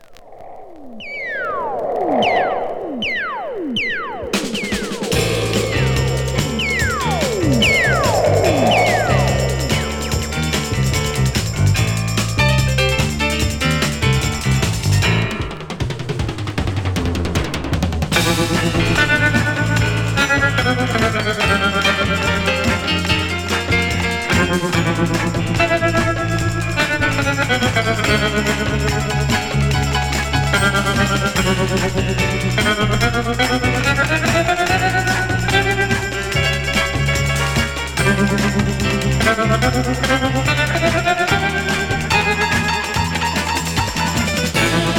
音の豪勢さと大人の遊びみたいな印象すらあるエンタメ感満載なレコード。
Rock, Stage & Screen　USA　12inchレコード　33rpm　Mono